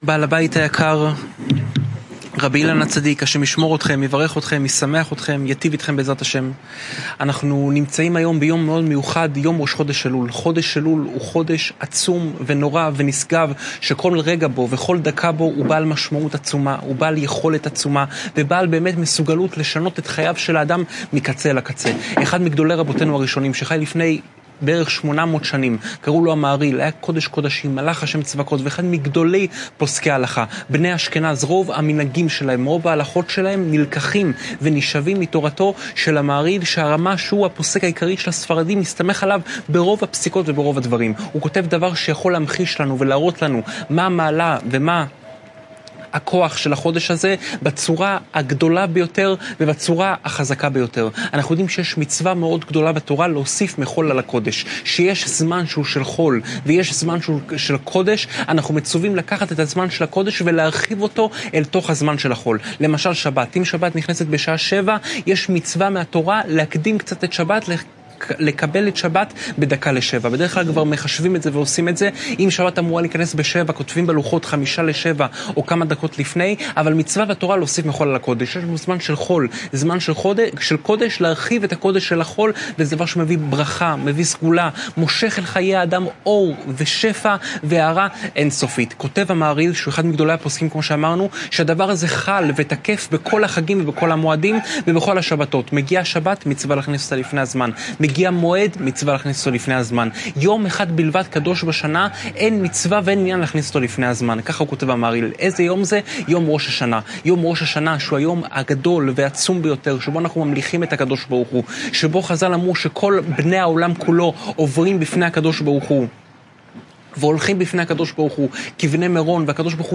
שעור תורה